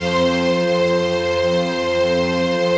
CHRDPAD067-LR.wav